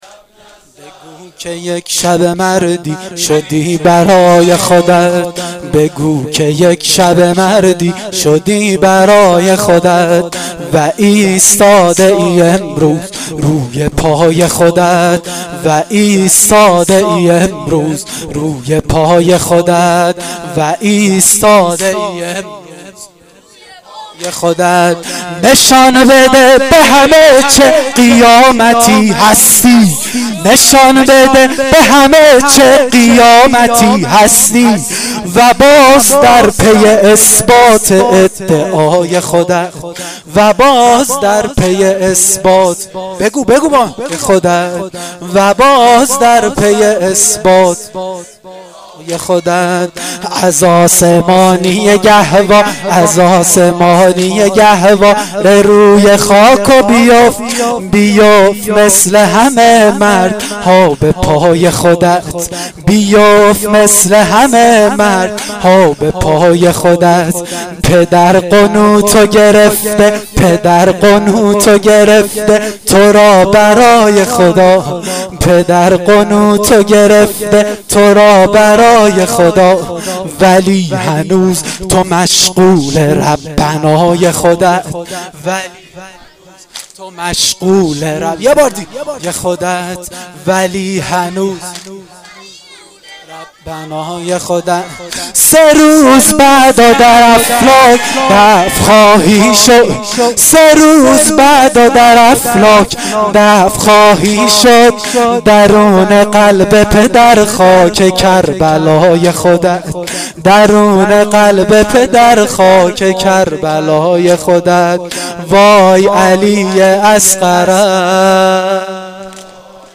واحد شب هفتم محرم1393